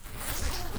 ZIPPER_Short_3_mono.wav